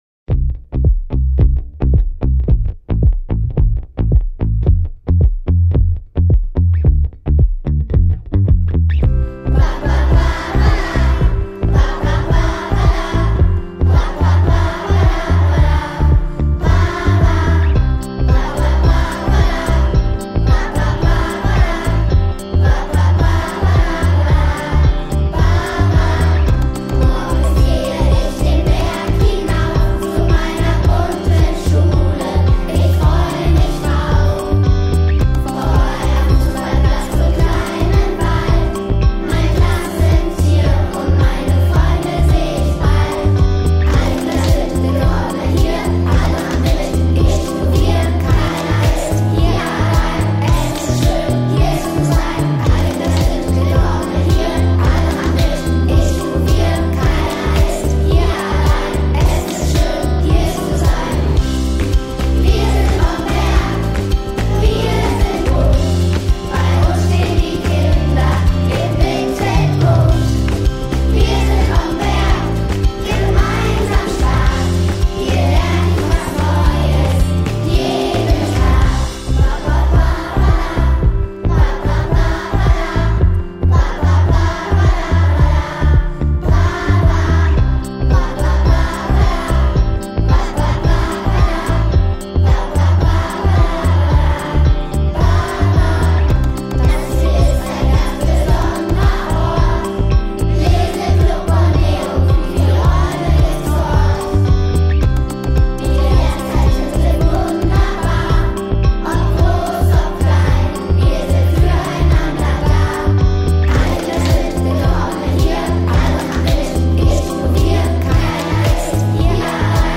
Das ist unser Schulsong!
SONG